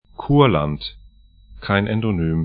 Kurland 'ku:ɐlant Kurzeme 'ku:ɐzɛmə lv Gebiet / region 57°18'N, 22°00'E